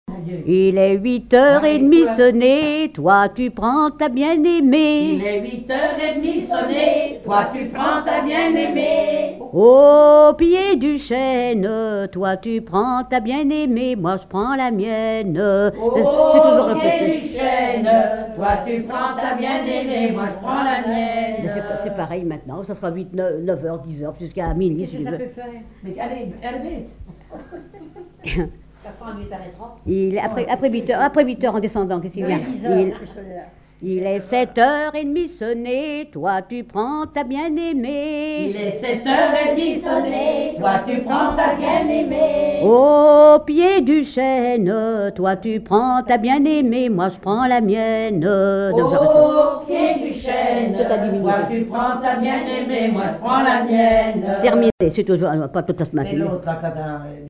Genre énumérative
collecte en Vendée
Pièce musicale inédite